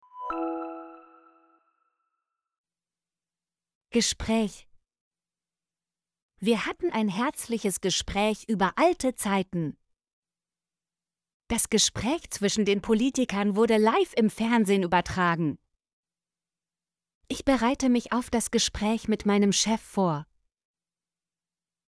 gespräch - Example Sentences & Pronunciation, German Frequency List